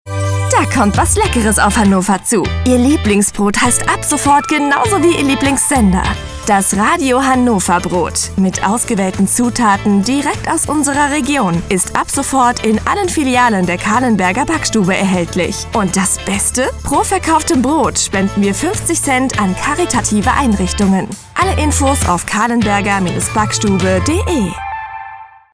Funkspot - Calenberger Backstube